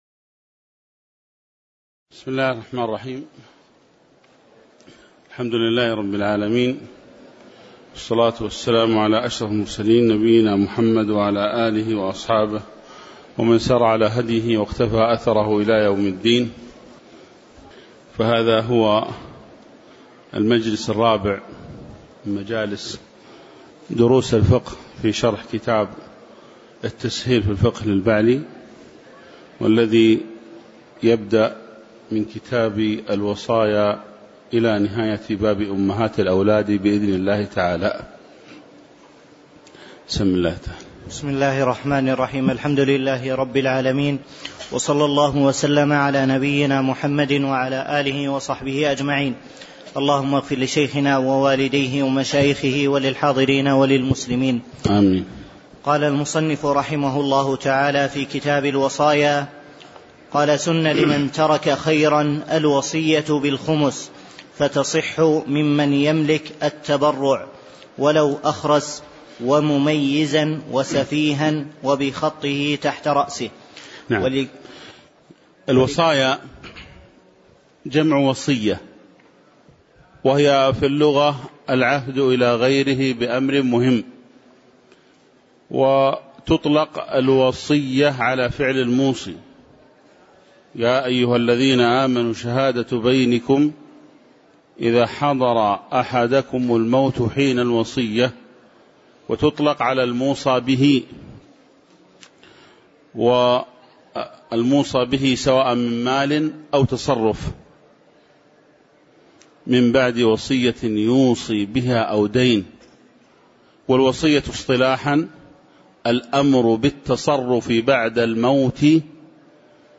تاريخ النشر ٢٠ شوال ١٤٣٩ هـ المكان: المسجد النبوي الشيخ